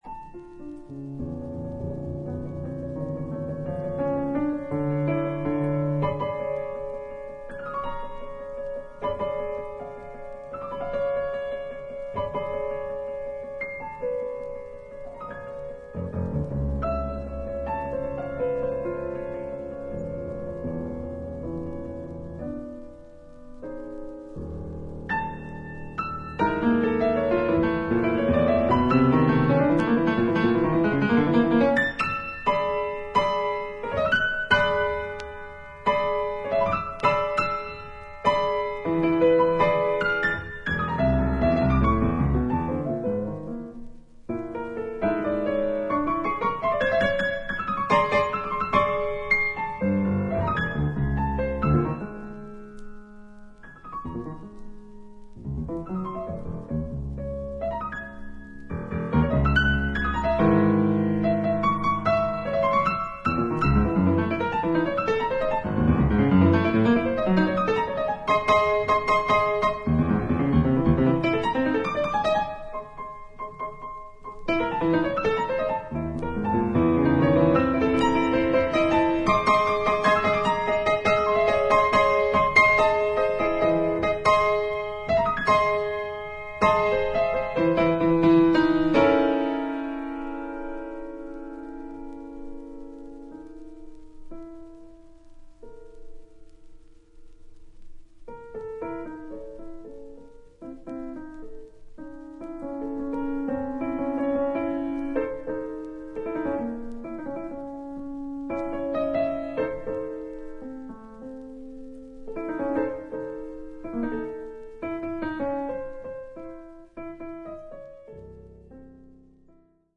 内省的でありながら、フェロー独特の穏やかな響きが感じられるピアノ独奏曲が計4曲収録。